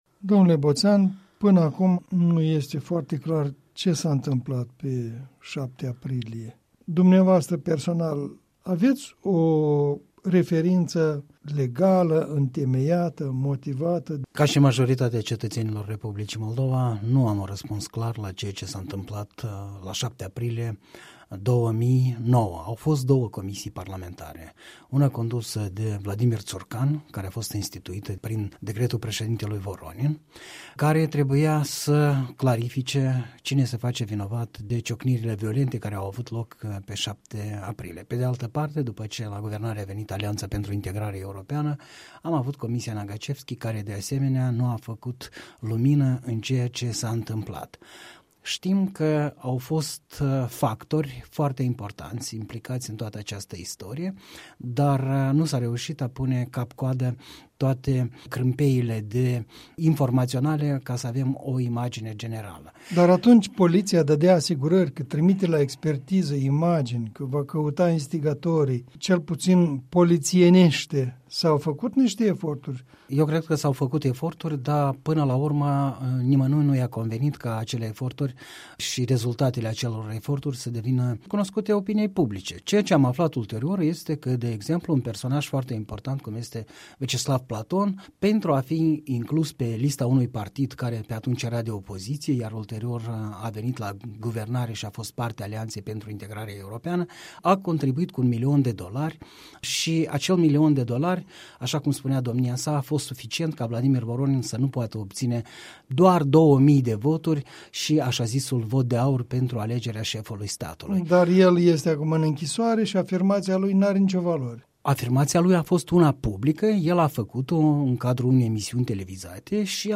Comentariu săptămânal, în dialog la Europa Liberă.